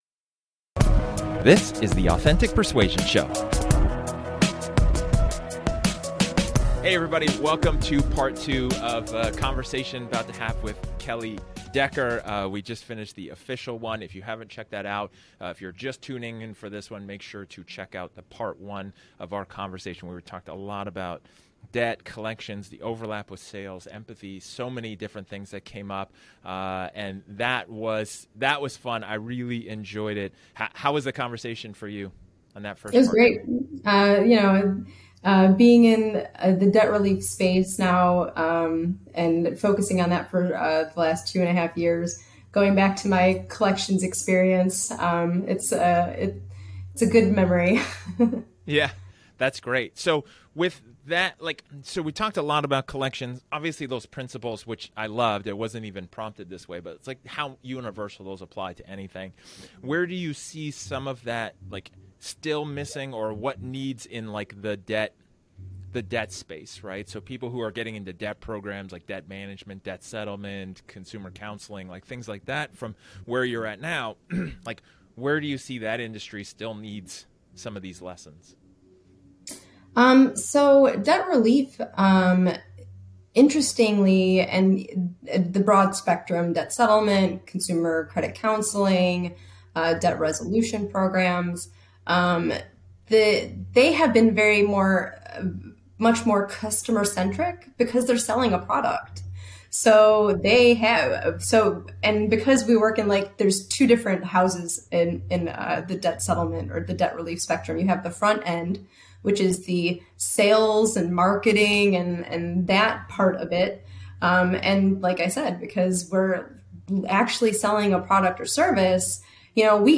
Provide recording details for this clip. This is a casual conversation, off the cuff, and unscripted.